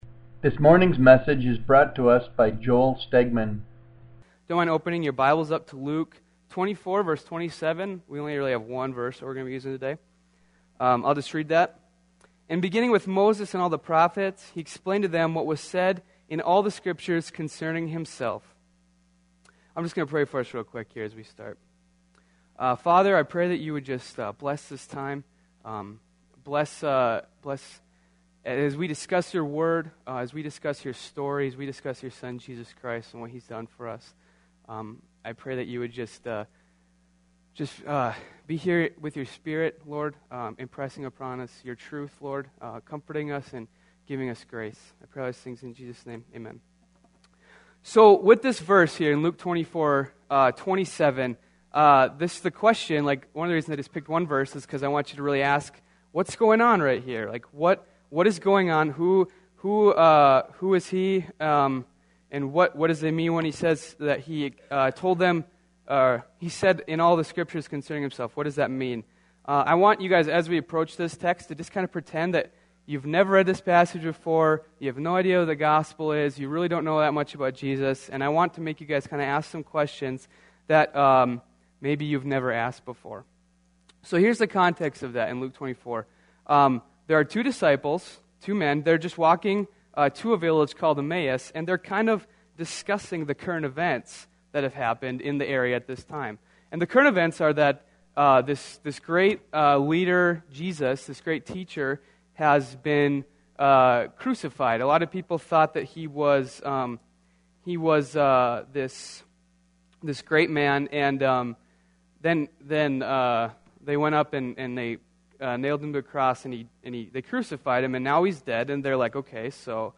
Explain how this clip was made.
Series: Sunday Service Topic: Knowing God